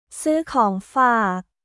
ซื้อของฝาก　スー・コーン・ファーク